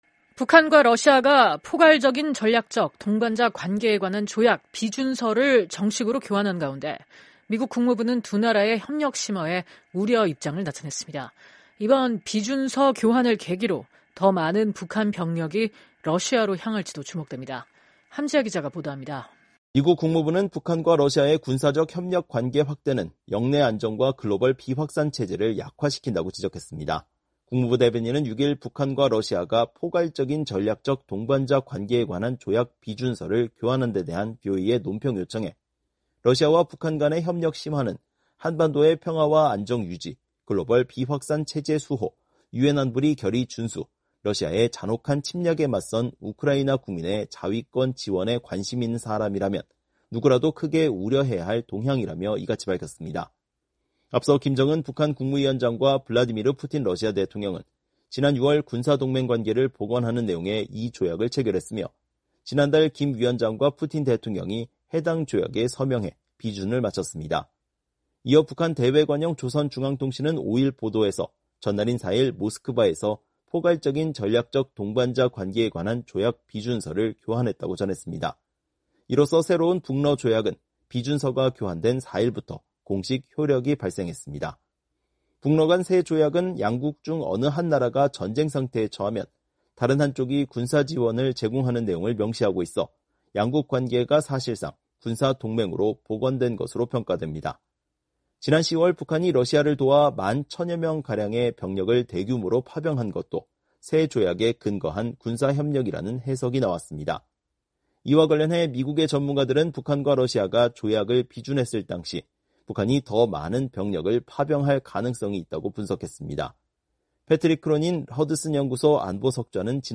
보도합니다.